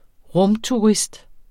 Udtale [ ˈʁɔmtuˌʁisd ]